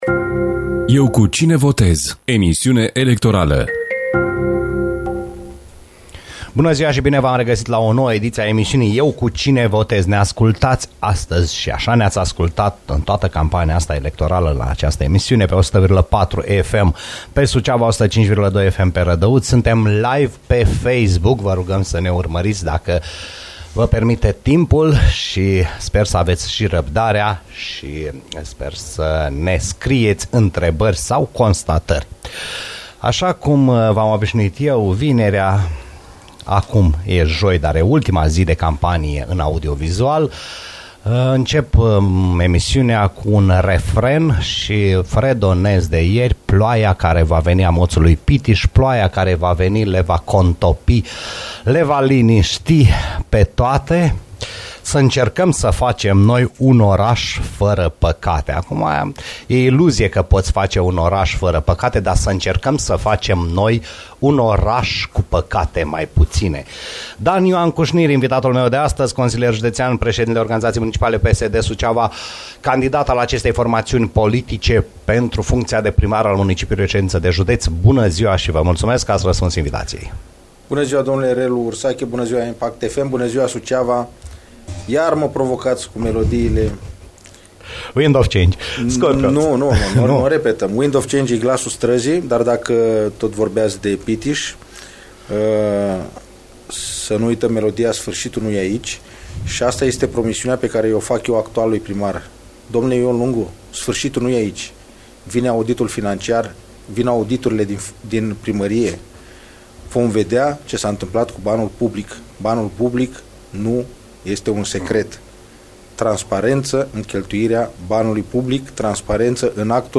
a fost invitat astăzi la emisiunea electorală „Eu cu cine votez ?”